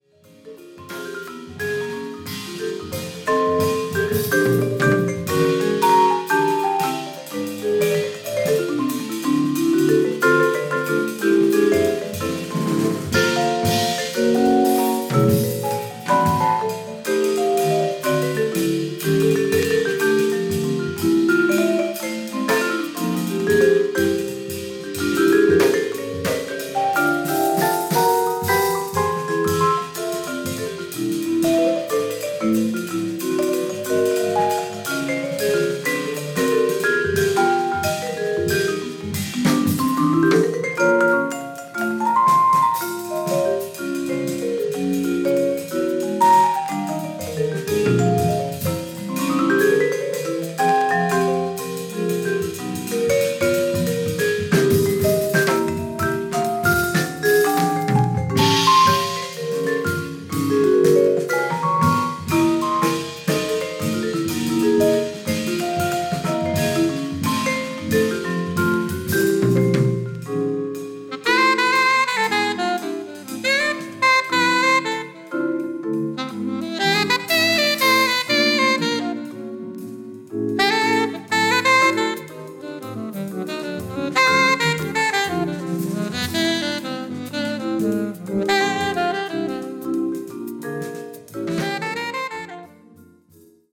Alto Saxophone
Bass
Drums
Vibraphone